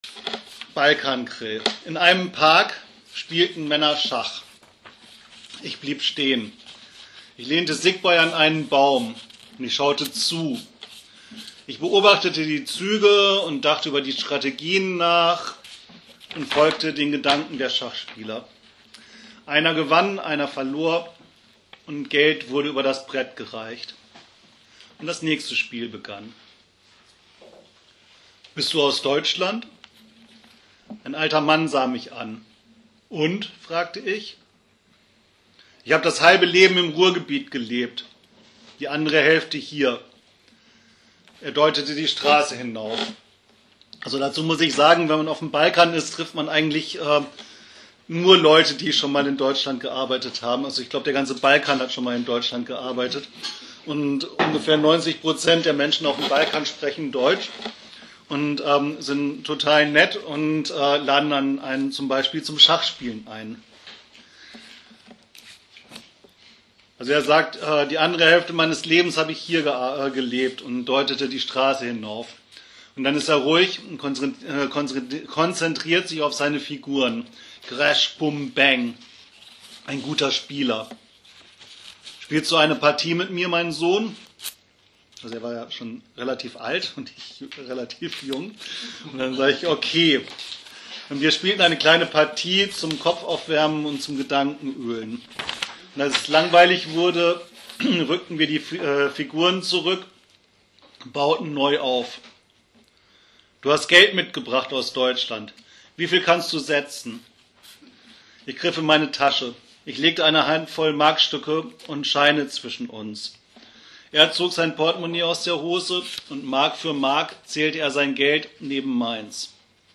Bei der Mitternachtslesung ins Cafe Cralle auf der Bühne des Lesetresen.